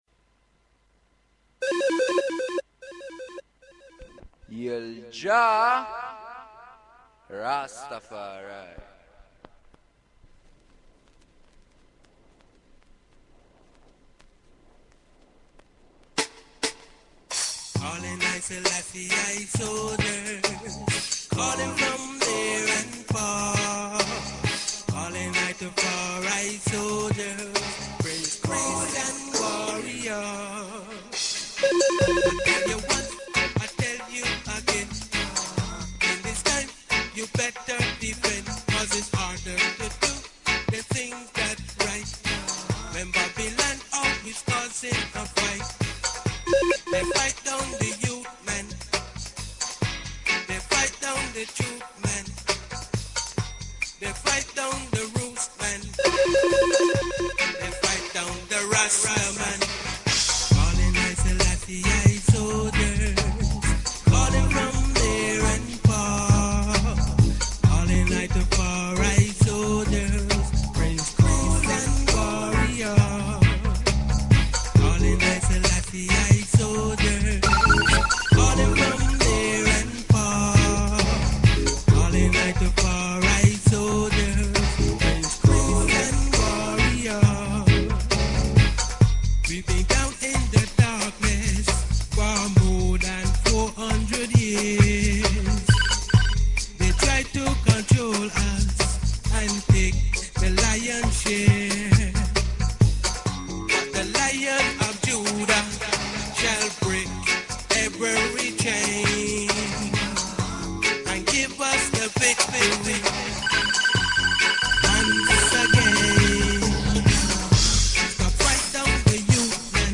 Chant Ises unto the King!